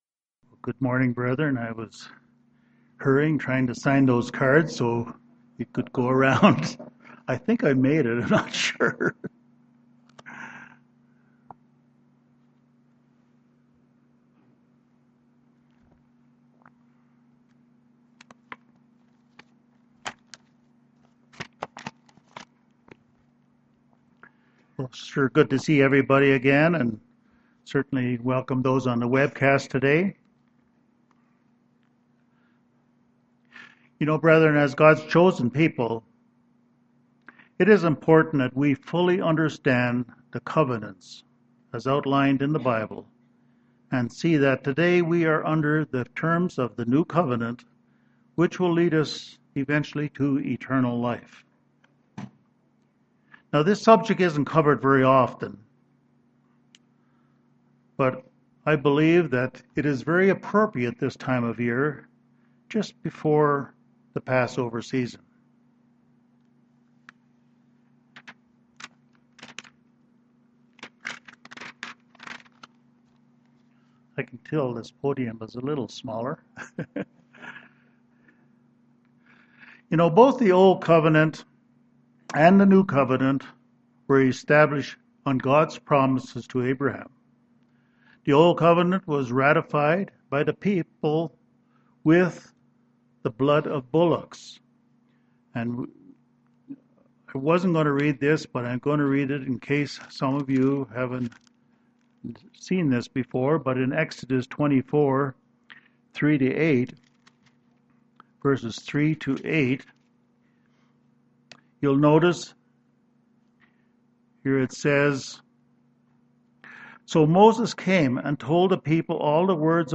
Given in Denver, CO
UCG Sermon Studying the bible?